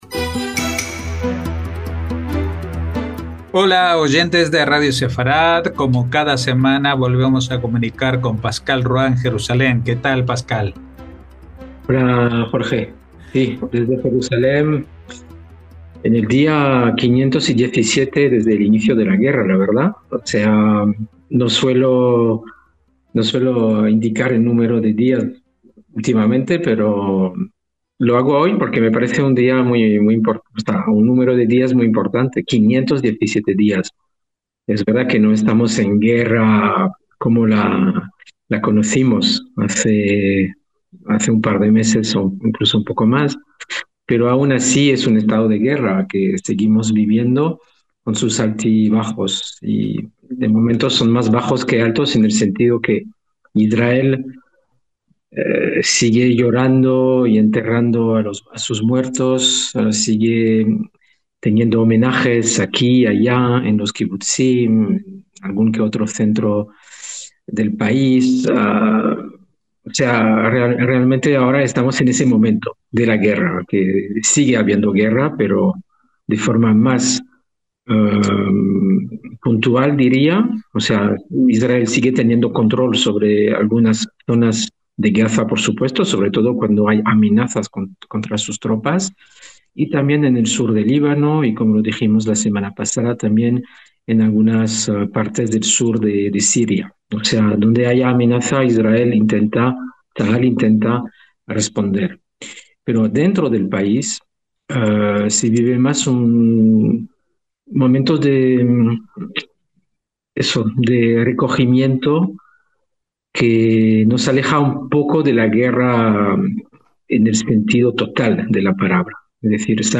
NOTICIAS CON COMENTARIO A DOS